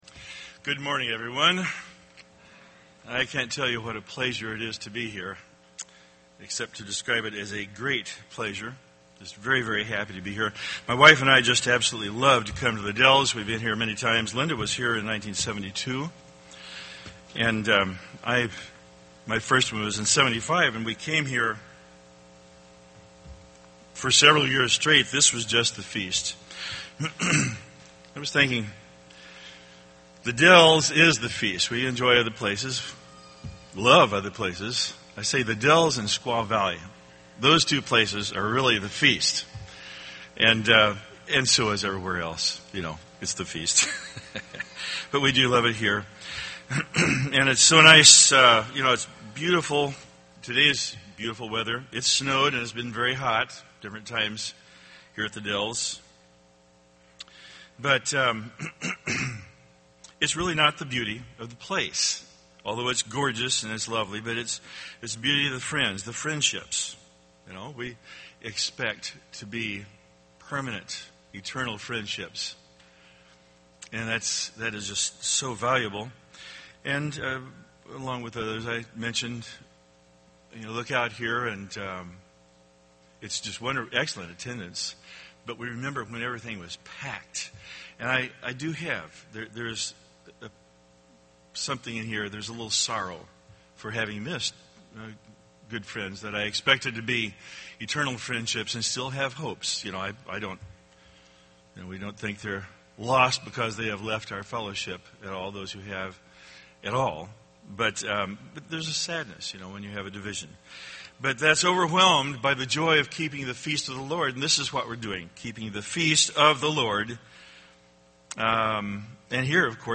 This sermon was given at the Wisconsin Dells, Wisconsin 2012 Feast site.